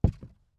ES_Walk Wood Creaks 12.wav